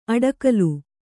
♪ aḍakalu